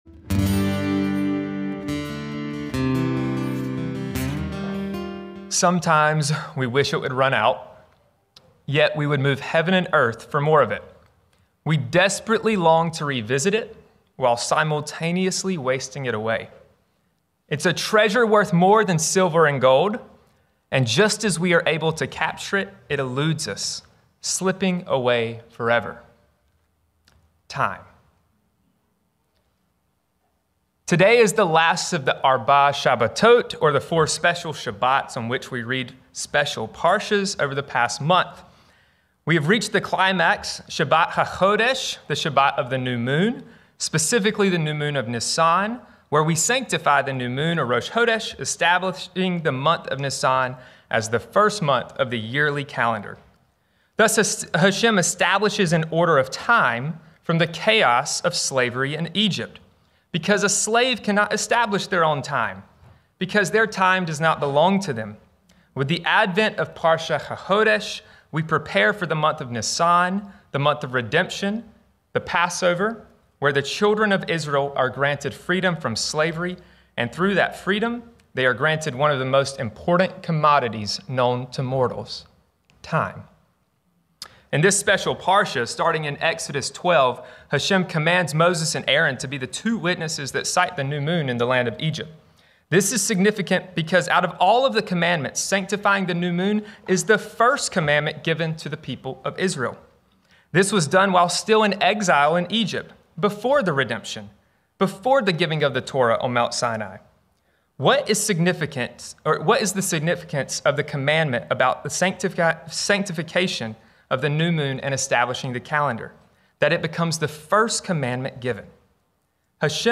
In this teaching for Shabbat HaChodesh, we explore the first commandment given in Egypt: sanctifying time. Why would God give Israel control of the calendar before the Exodus even happened?